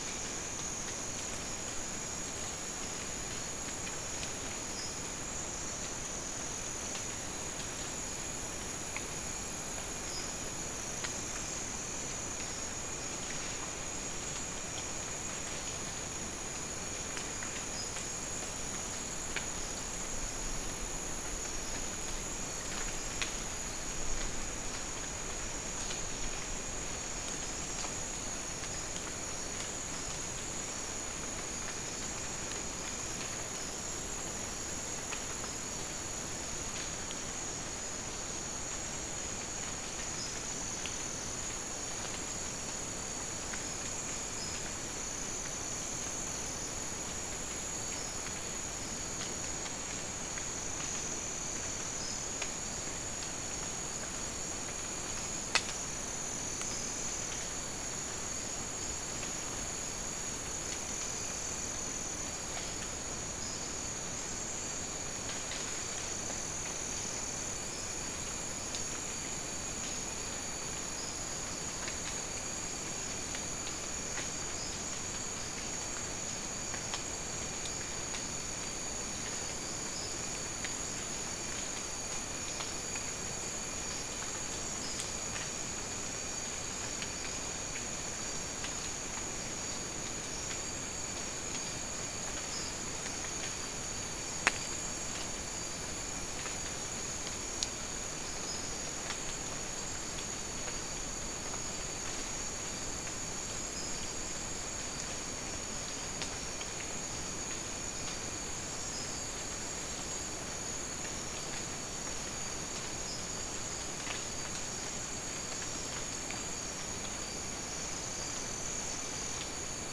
Soundscape Recording
South America: Guyana: Rock Landing: 1
Recorder: SM3